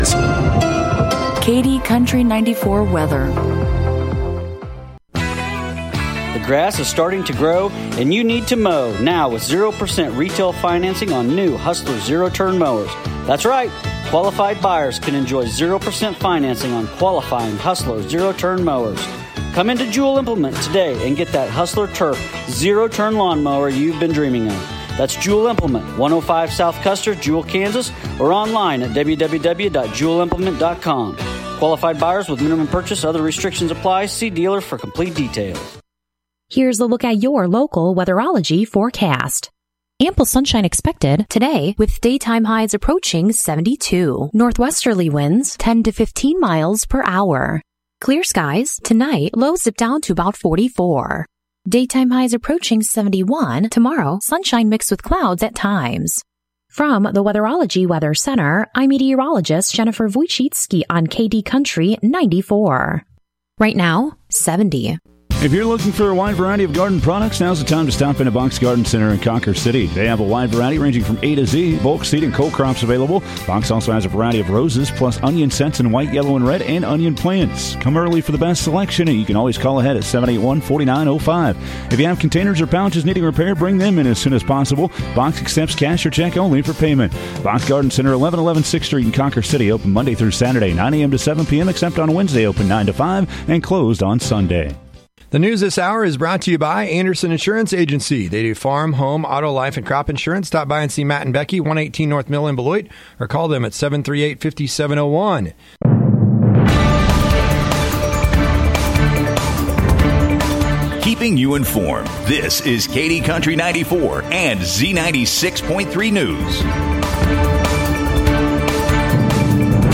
🎙 KD Country 94 Local News, Weather & Sports – 5/8/2024